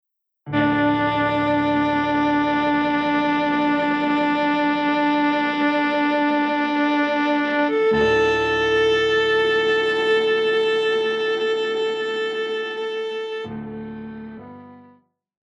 Classical
Viola
Piano
Solo with accompaniment